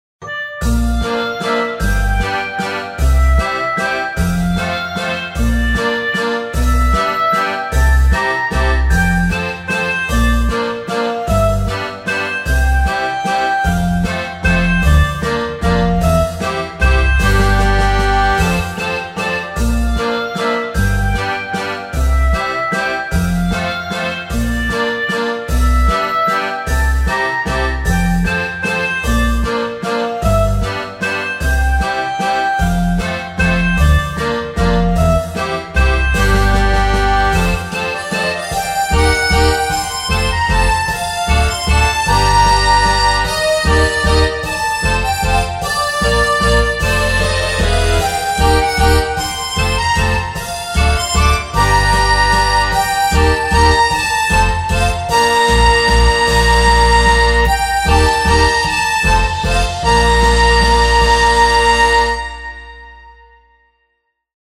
中世ヨーロッパ風の街並みやにぎやかな市場、石畳の路地を想像させるような明るさと懐かしさを兼ね備えた一曲となっています。
• 編成：クラリネット系の木管、ハープ、アコースティックベース、ベル系パッドなど
• テンポ：落ち着いた中速テンポ
• 調性：メジャー系で安心感を演出